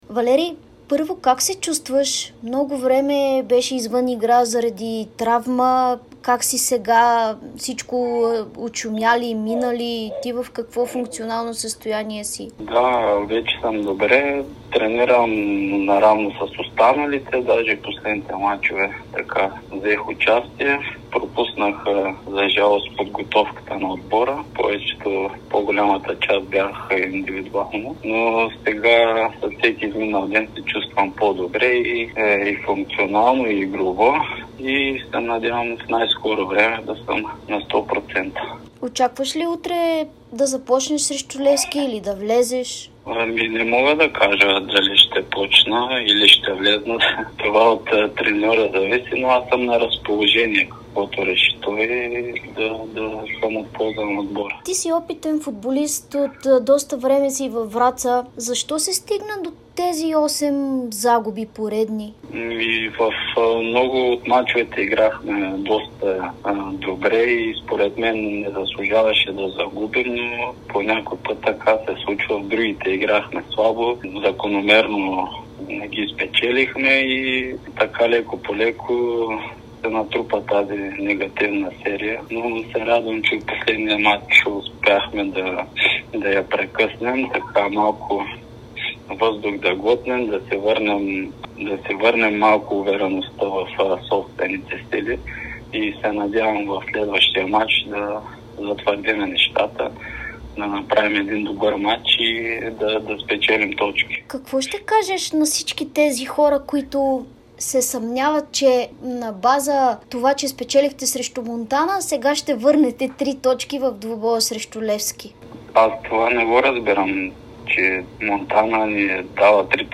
специално интервю за Дарик радио и dsport